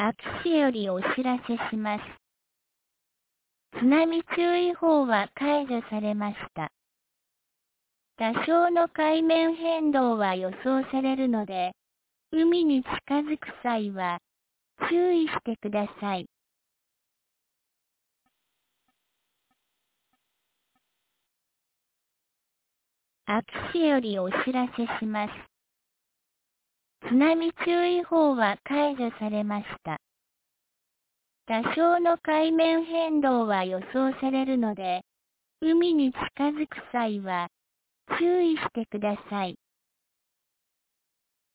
2023年10月09日 12時14分に、安芸市より全地区へ放送がありました。